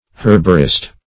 herbarist - definition of herbarist - synonyms, pronunciation, spelling from Free Dictionary
Herbarist \Herb"a*rist\, n.